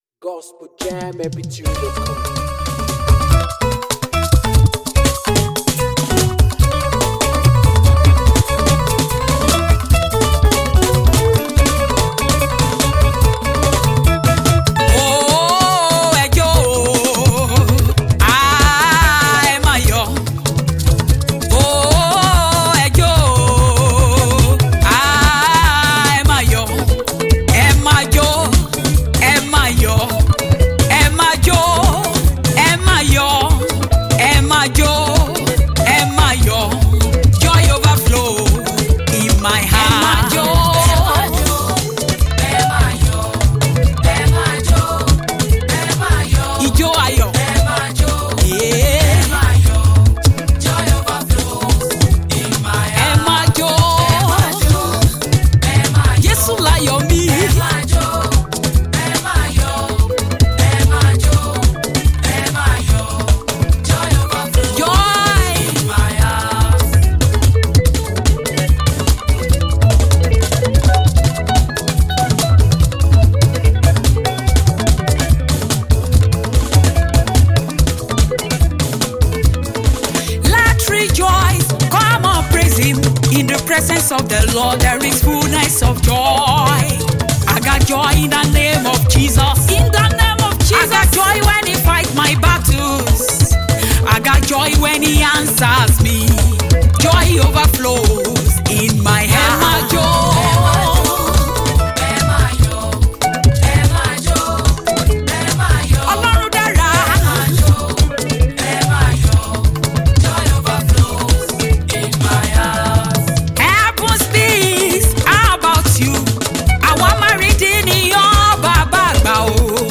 a song of praise and Thanksgiving